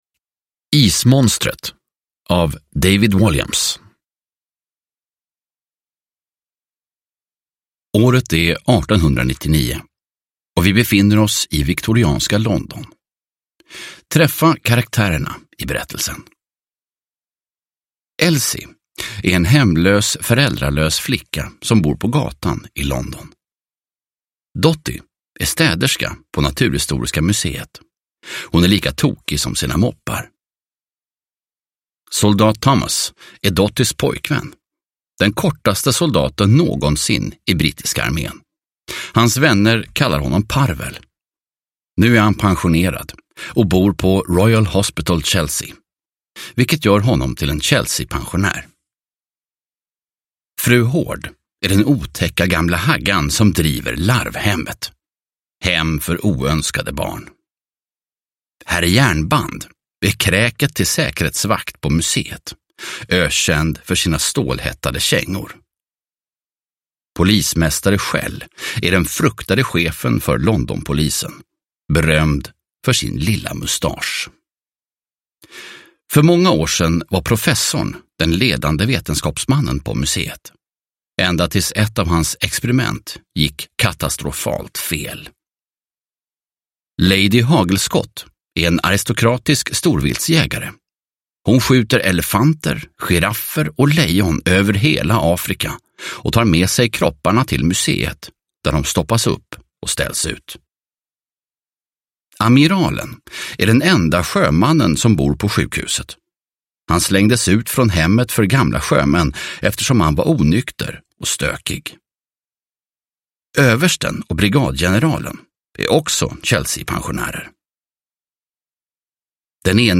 Ismonstret – Ljudbok – Laddas ner
Uppläsare: Fredde Granberg